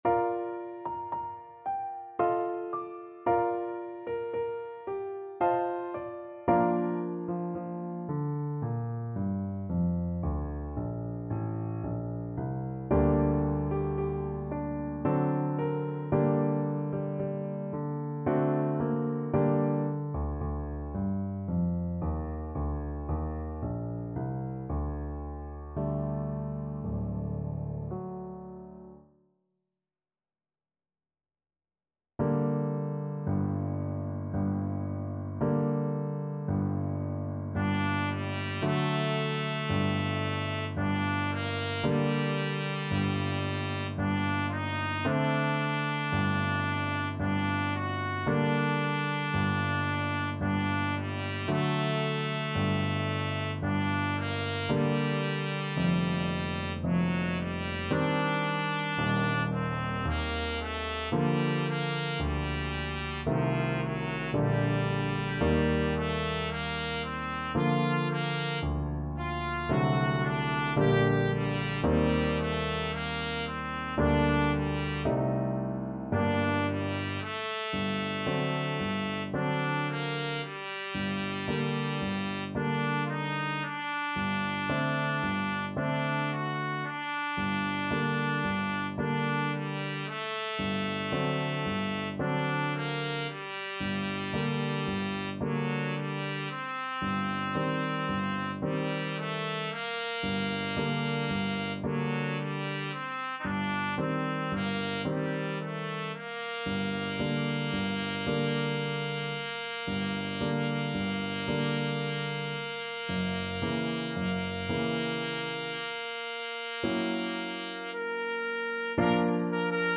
Trumpet
Bb major (Sounding Pitch) C major (Trumpet in Bb) (View more Bb major Music for Trumpet )
~ = 56 Andante
3/4 (View more 3/4 Music)
Classical (View more Classical Trumpet Music)
tchaik_serenade_melancolique_TPT.mp3